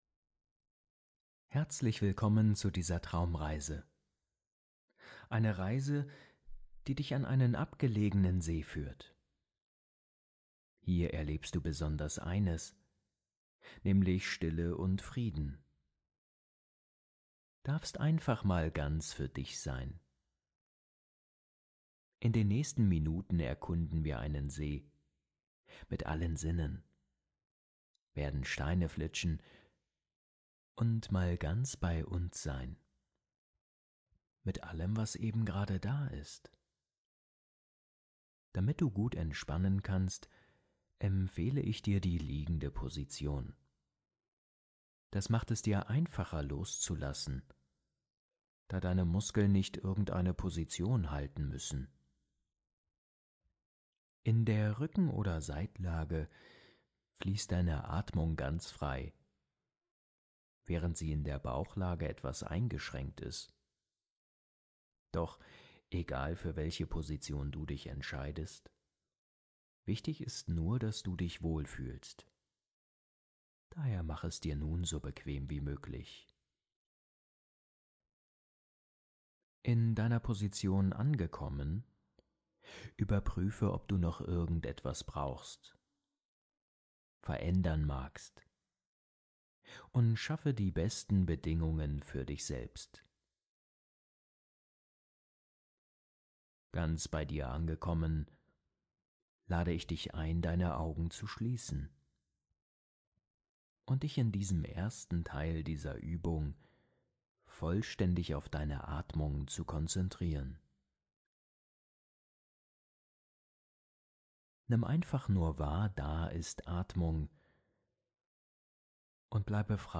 Traumreise - Steine flitschen und loslassen